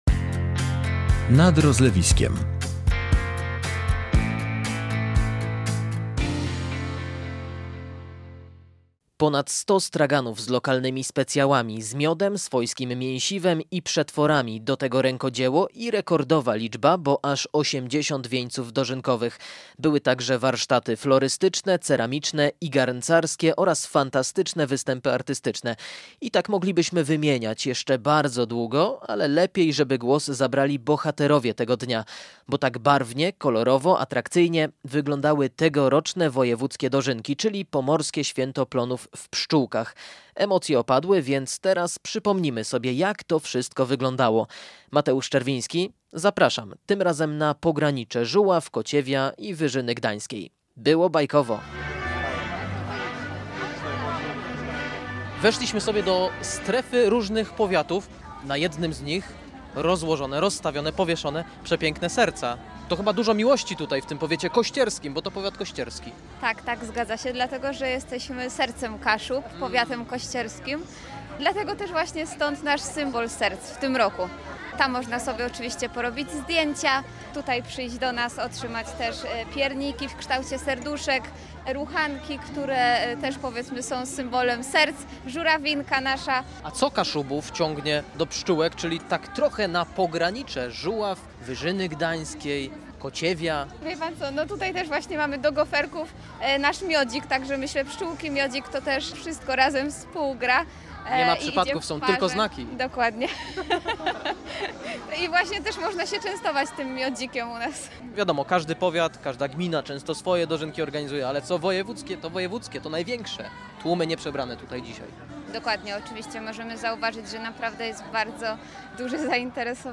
I tak moglibyśmy wymieniać jeszcze bardzo długo, ale lepiej, żeby głos zabrali bohaterowie tego dnia.